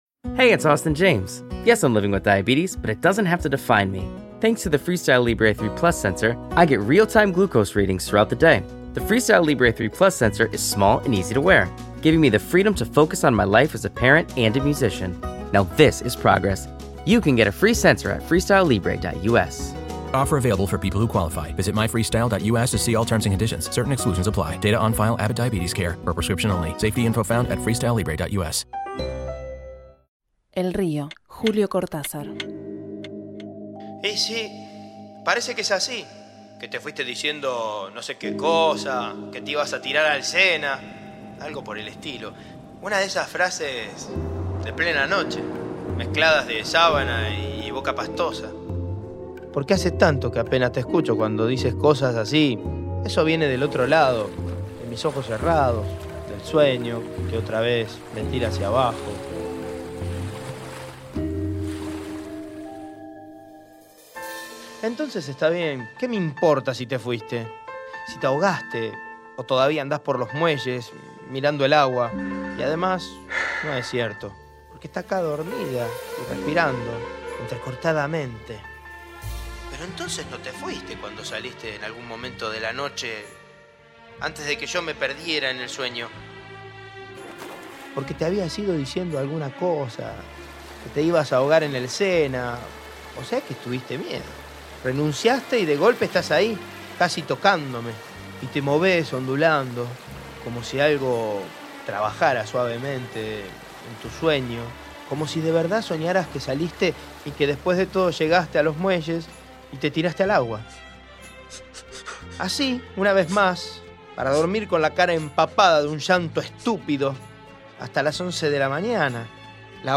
Adaptación radiofónica del cuento El río publicado en Final de juego (1956), por Julio Cortázar.